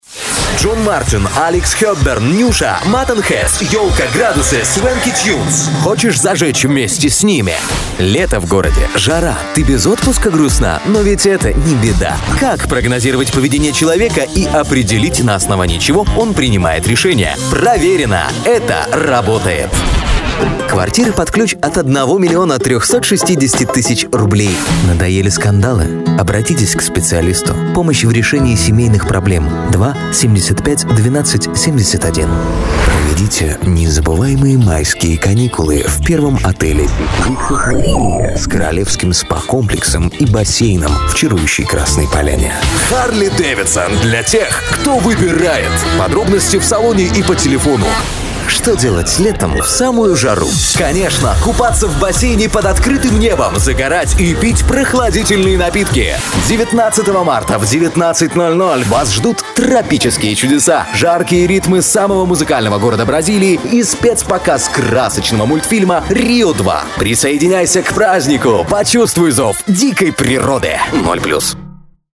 Шумоизолированная комната, мапписил. Микрофон AKG C3000,Symetrix 528E Voice Processor
Демо-запись №1 Скачать